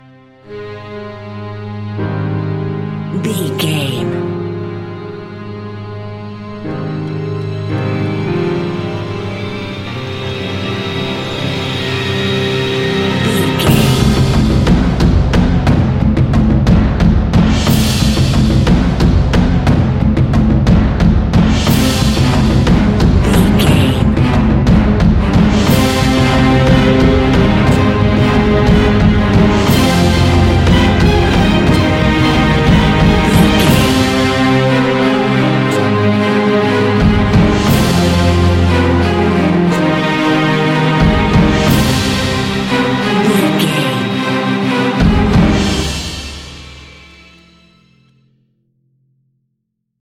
Aeolian/Minor
scary
tension
ominous
dark
suspense
eerie
strings
brass
percussion
violin
cello
double bass
taiko drums
timpani